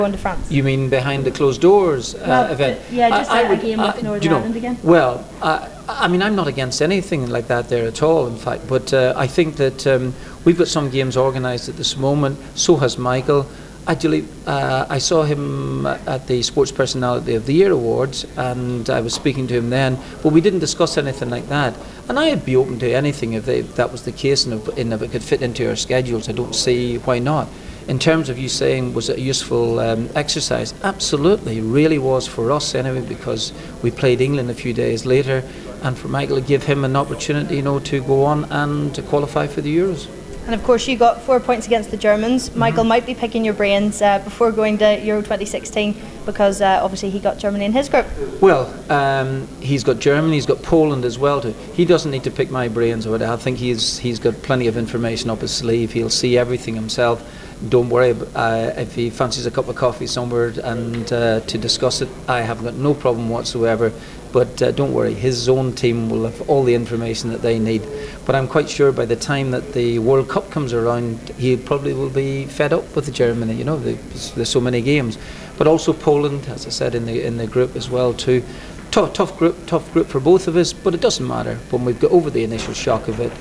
Martin O'Neill speaks to us at the Belfast Telegraph Sports Awards ahead of the Euro 2016 Finals.